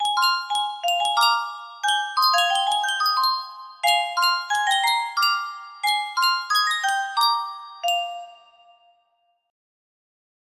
adjusted to fit on 18 note